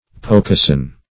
Search Result for " pocoson" : The Collaborative International Dictionary of English v.0.48: Pocoson \Po*co"son\, n. Low, wooded grounds or swamps in Eastern Maryland and Virginia.